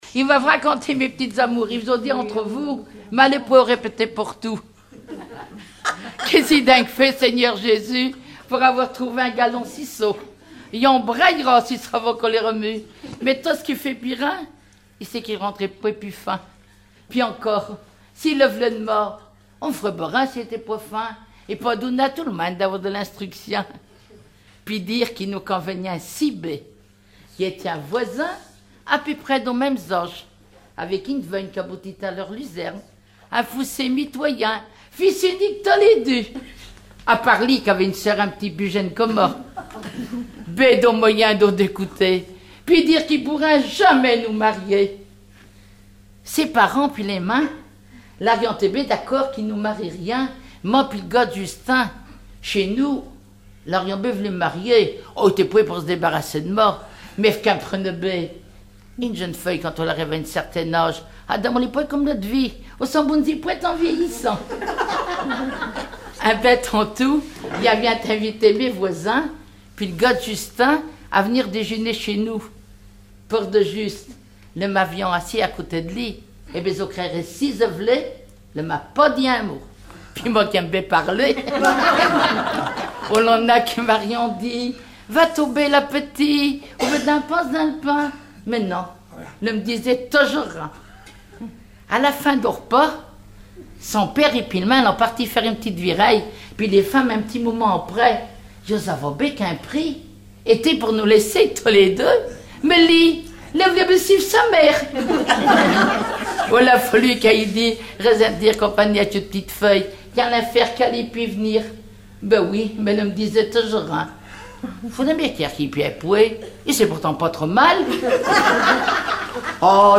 Genre sketch
Regroupement de chanteurs du canton
Catégorie Récit